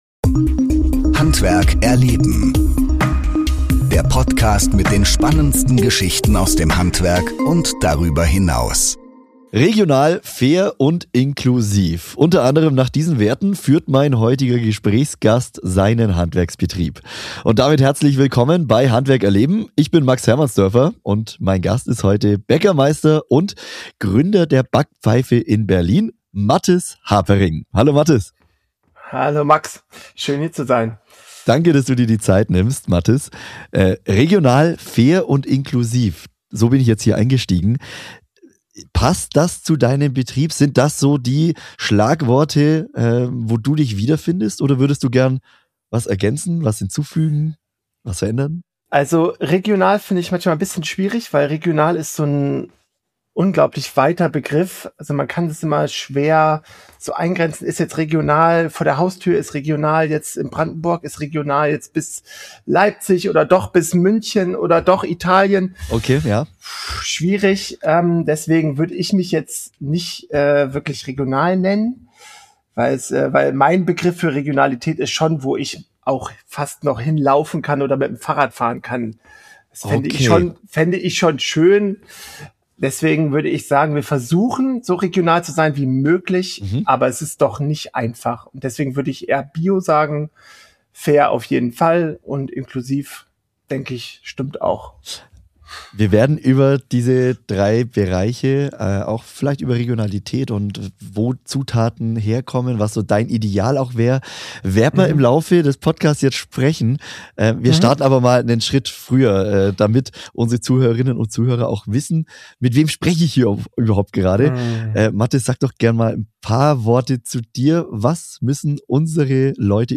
Im Gespräch geht es darum, wie Zusammenarbeit auch ohne gemeinsame Lautsprache funktionieren kann, welche Herausforderungen in Ausbildung und Berufsschule entstehen und welche Unterstützung Betriebe erhalten können.